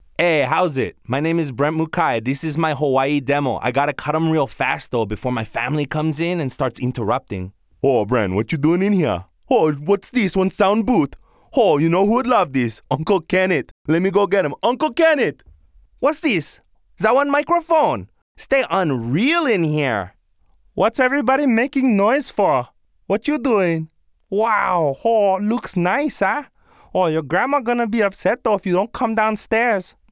Teenager, Young Adult, Adult
Has Own Studio
pasifika | natural